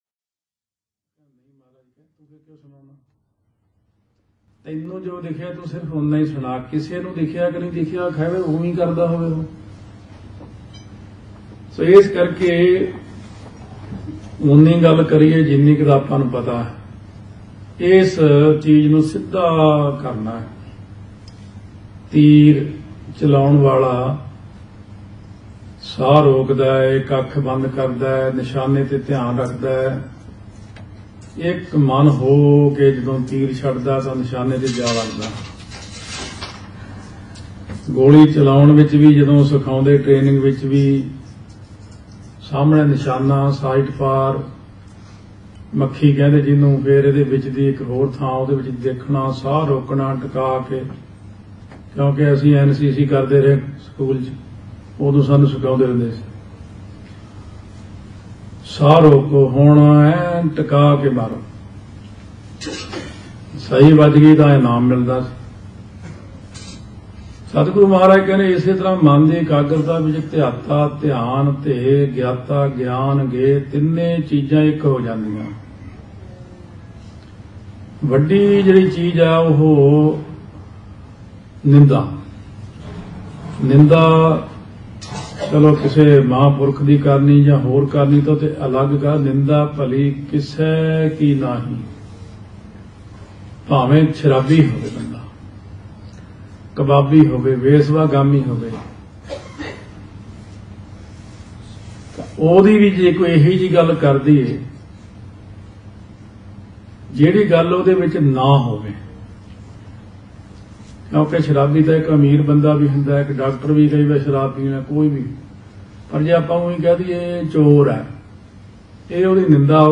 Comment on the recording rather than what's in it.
July17 2012 UK Tour